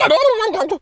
turkey_ostrich_hurt_gobble_03.wav